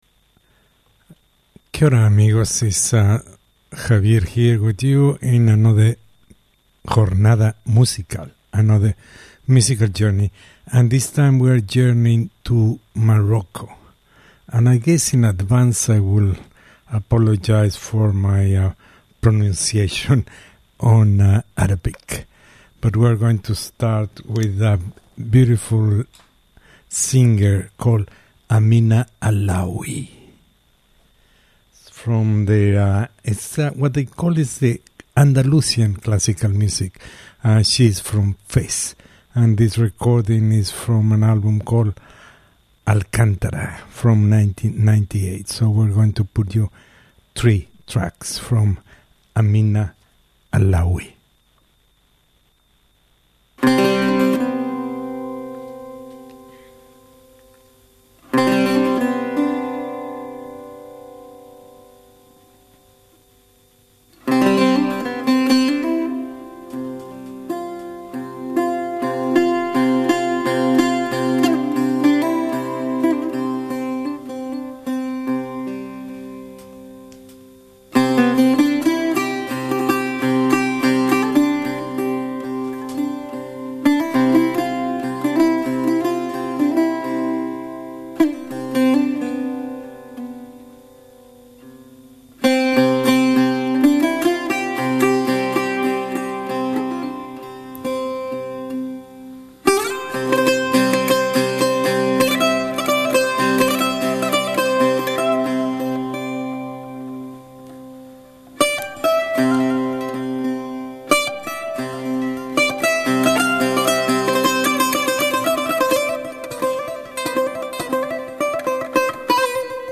Music from Morocco.